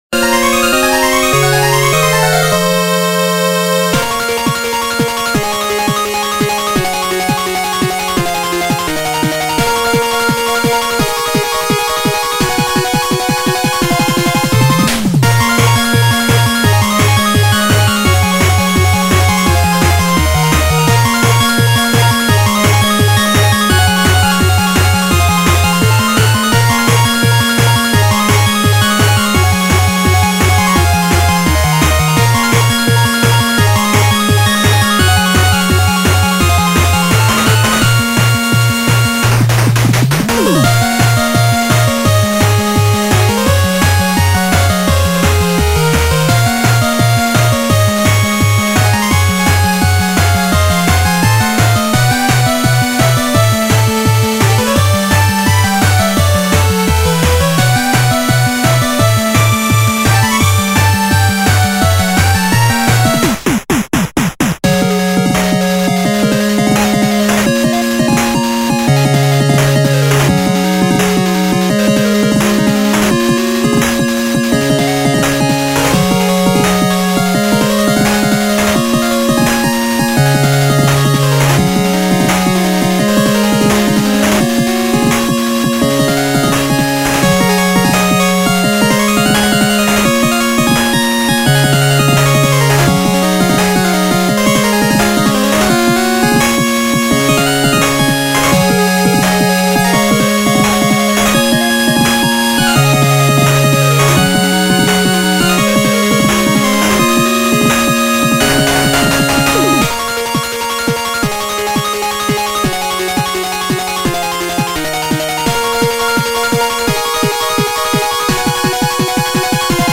ジャンルファミコン風
使用例ボス戦闘曲、ラスボスへの演出
BPM１５０→１７０
使用楽器8-Bit音源